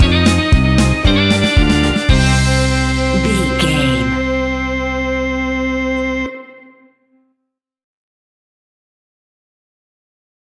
Aeolian/Minor
scary
tension
ominous
dark
suspense
eerie
energetic
groovy
drums
electric guitar
bass guitar
piano
synthesiser
Horror Synths